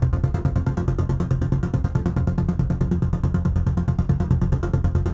Index of /musicradar/dystopian-drone-samples/Tempo Loops/140bpm
DD_TempoDroneC_140-F.wav